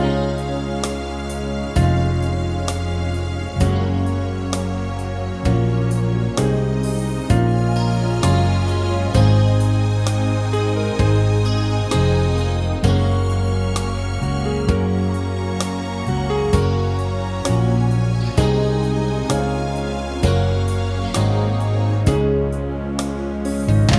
no Backing Vocals Duets 4:18 Buy £1.50